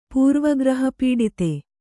♪ pūrva graha pīḍite